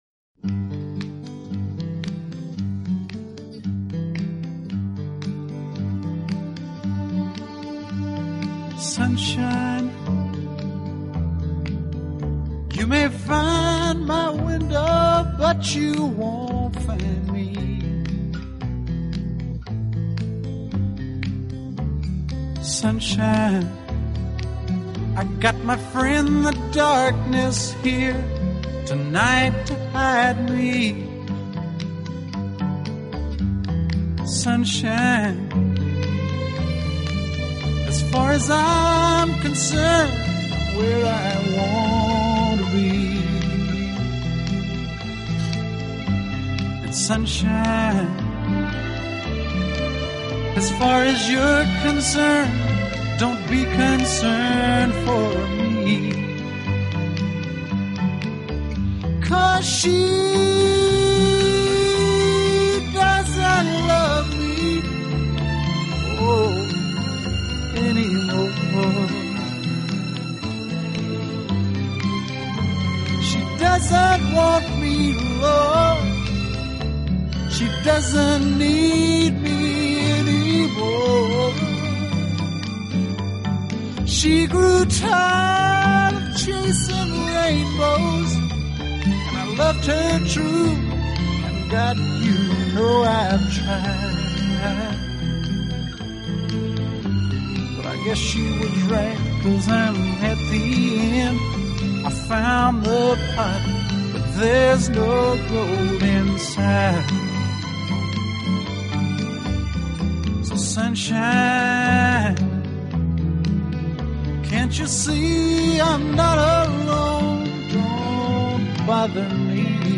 【乡村歌曲】